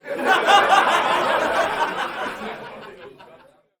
teenageCrowdLaugh.ogg